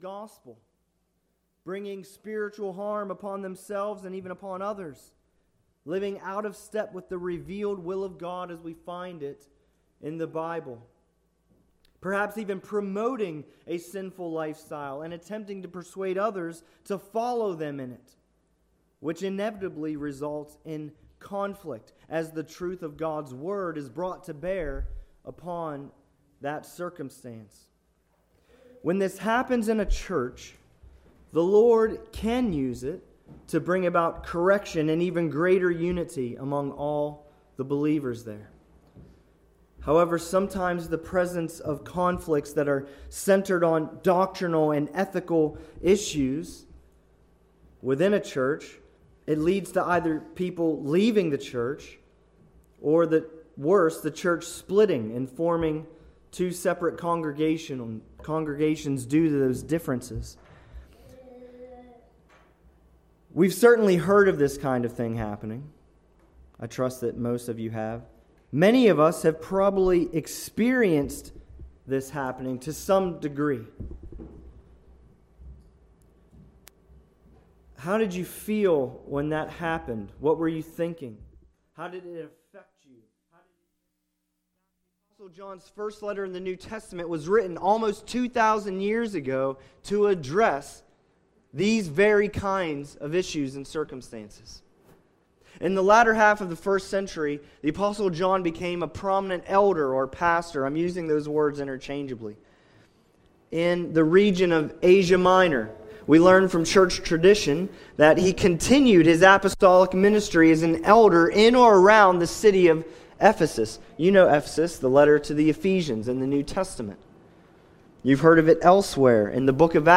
(partial recording) Speaker